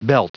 Prononciation du mot belt en anglais (fichier audio)
Prononciation du mot : belt